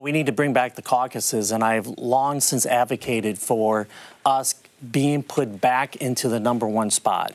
Meyer says the Caucuses help Democrats register new voters and start precinct level organizing for the General Election. Meyer made his comments during taping of the "Iowa Press" program on Iowa P-B-S. Some Iowa Democrats say the Caucuses have been a distraction from local races and it's time to give up the fight to have the Democratic Party's Caucuses go first in 2028.